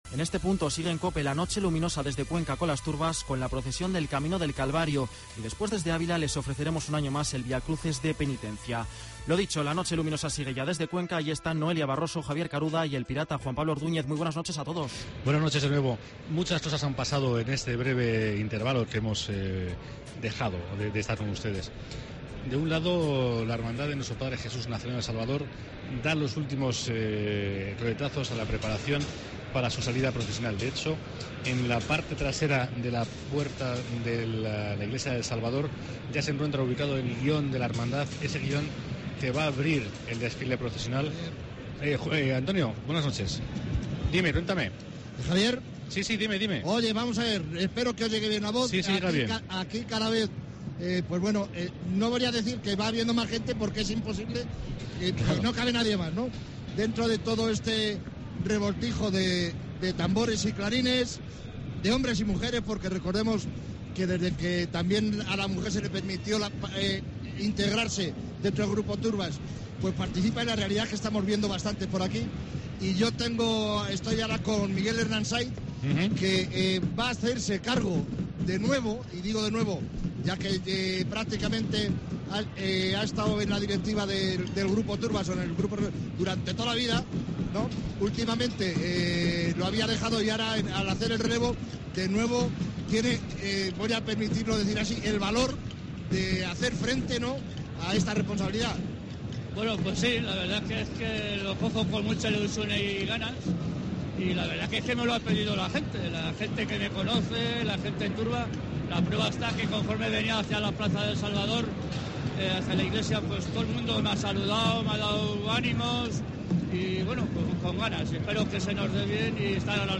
AUDIO: La Voz de la Pasión retransmisión Camino del Calavario de 05.00 a 05.30 horas